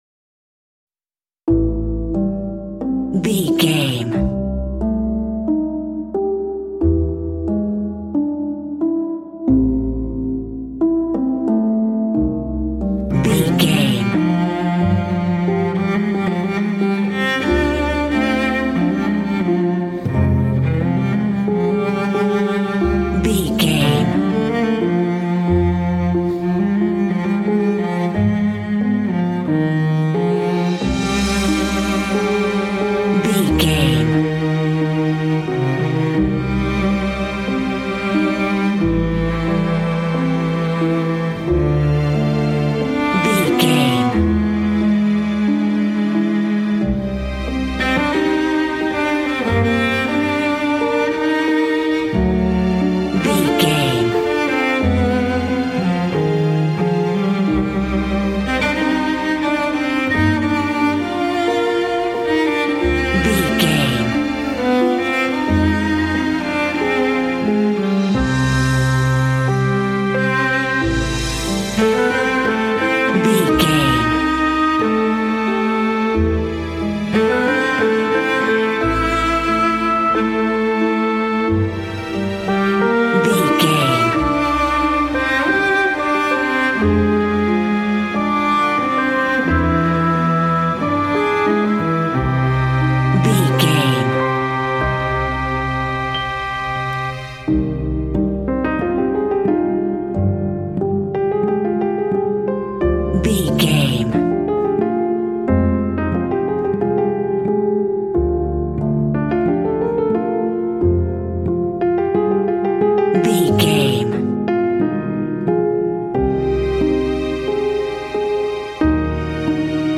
Aeolian/Minor
Slow
meditative
melancholy
mournful
oboe
symphonic
cinematic